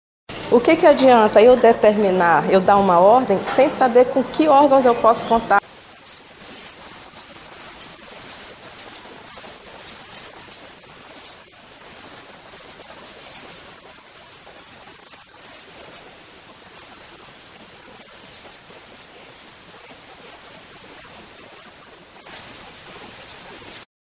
Para a juíza da 4ª Vara de Violência Contra a Mulher, Eline Paixão, que atua com os julgamentos de casos, essa integração da rede de proteção garante que mais mulheres saibam que há espaços onde elas serão acolhidas, caso sejam vítimas de violências.